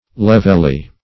levelly - definition of levelly - synonyms, pronunciation, spelling from Free Dictionary Search Result for " levelly" : The Collaborative International Dictionary of English v.0.48: Levelly \Lev"el*ly\, adv. In an even or level manner.